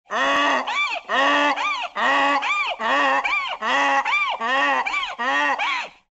Звуки ослов
Смешной звук ослиного смеха